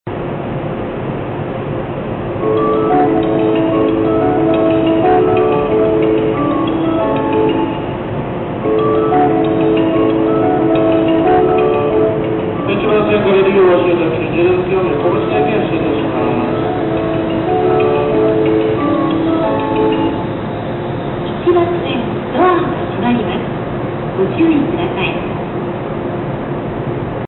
このホームは、発車メロディが２･３番線とは異なります。
ATOS第１号線ということで、女声の放送しかありません。
このメロディは、以前府中本町で使われていたメロディです。